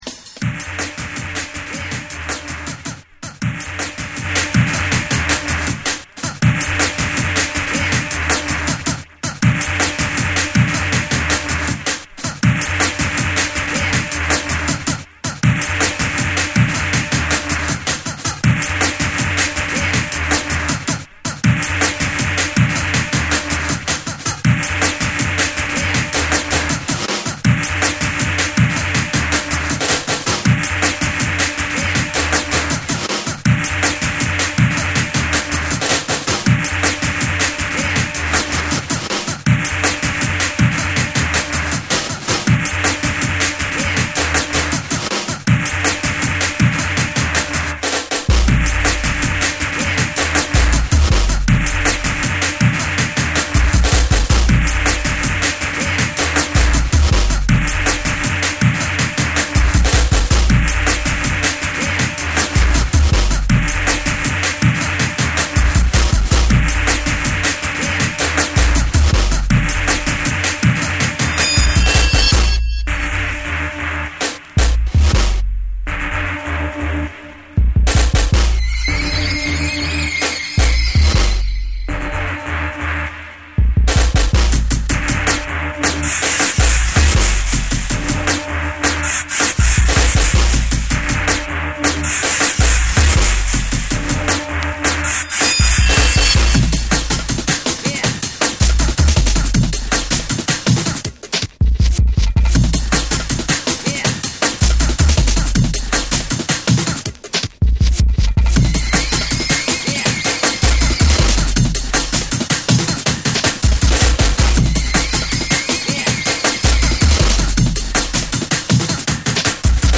重厚なブレイクビーツ、深みのあるベースライン、浮遊感のあるシンセのメロディーが織りなすドラムンベース金字塔的アルバム！
JUNGLE & DRUM'N BASS